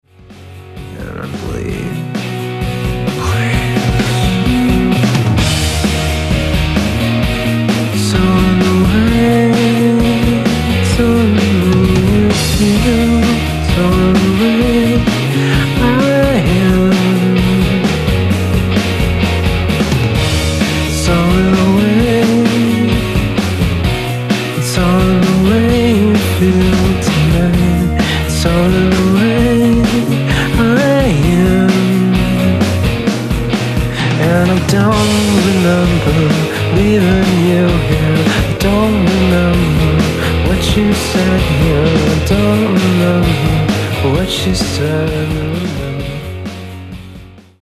Chapel Hill NC Rock Band